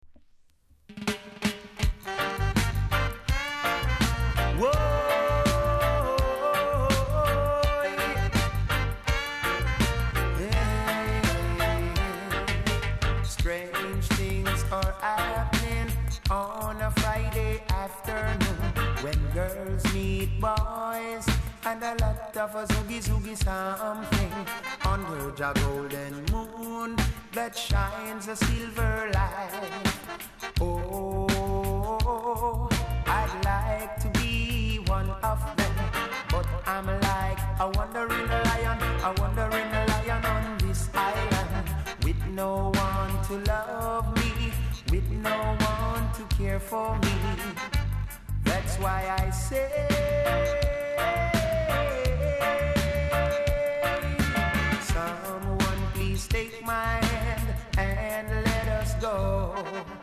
※小さなチリノイズが少しあります。盤は薄い擦り傷が少しありますがキレイな方です。